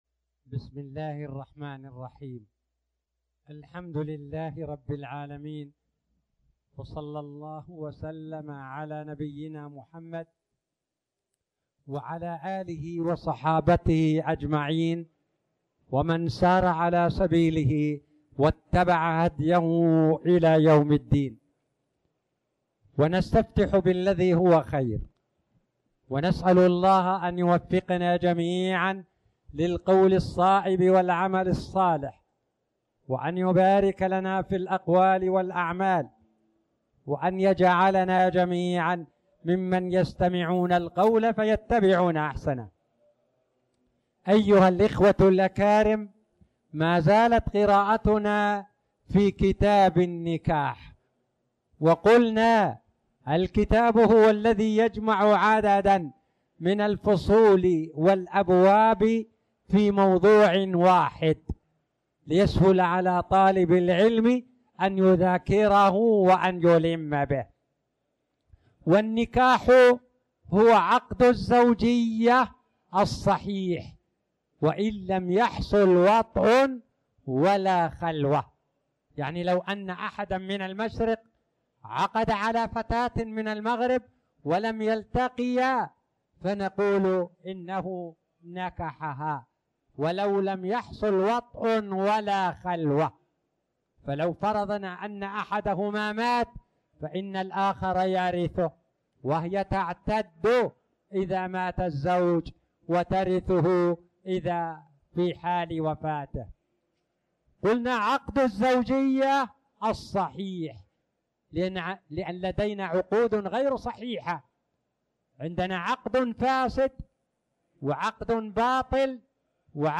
تاريخ النشر ٣ جمادى الأولى ١٤٣٨ هـ المكان: المسجد الحرام الشيخ